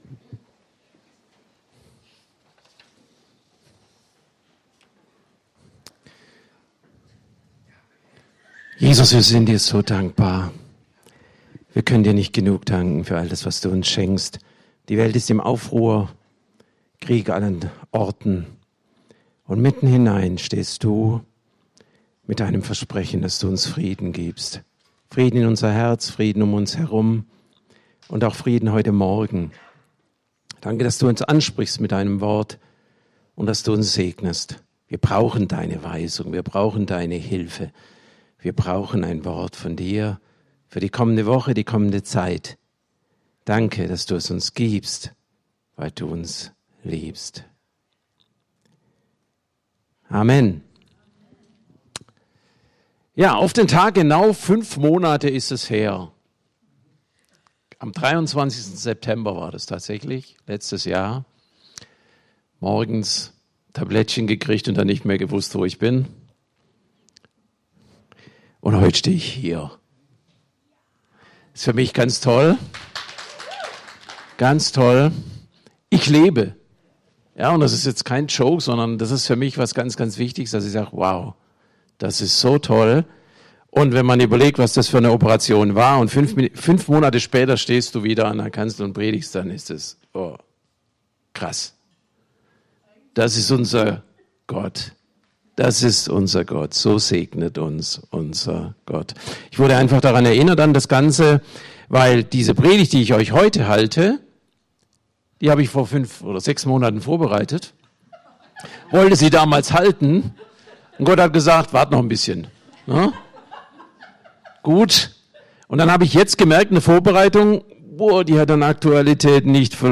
Predigt vom 23.02.2025 – Christliches Zentrum Günzburg